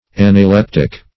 analeptic \an`a*lep"tic\ ([a^]n`[.a]*l[e^]p"t[i^]k), a. [Gr.